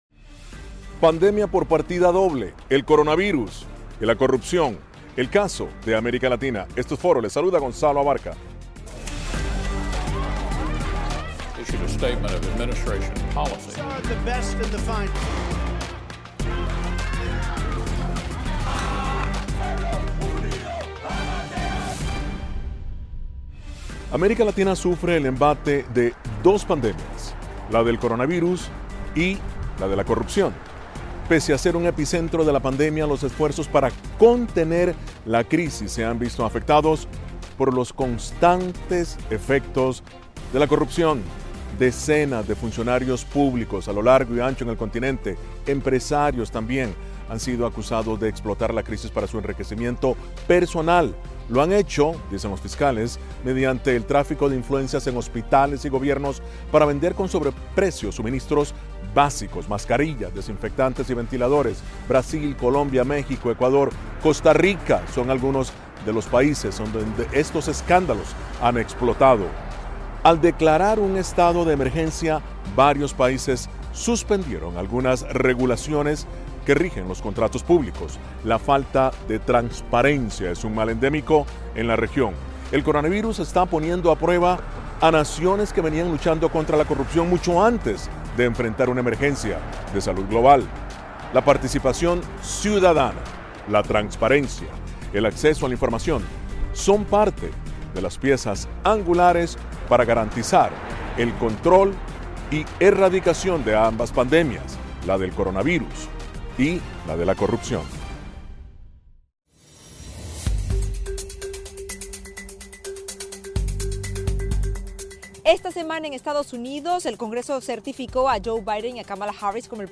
Foro: Coronavirus y corrupción doblegan al hemisferio